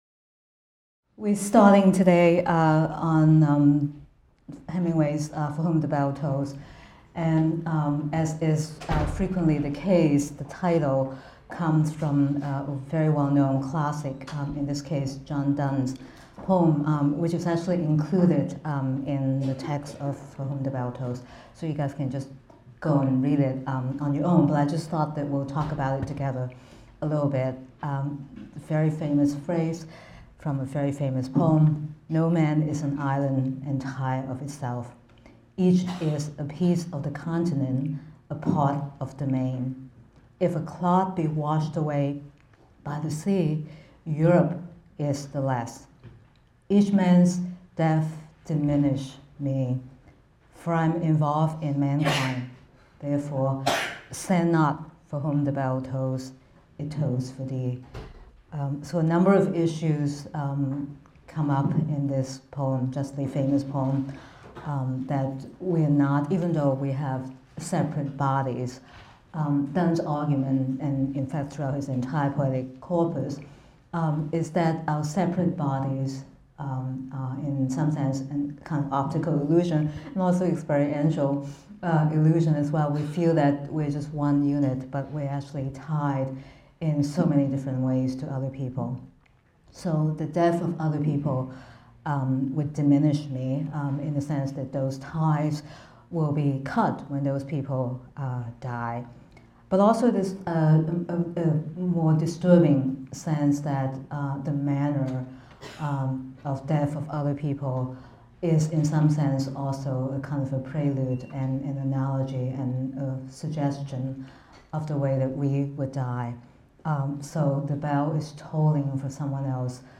AMST 246 - Lecture 16 - Hemingway’s For Whom the Bell Tolls | Open Yale Courses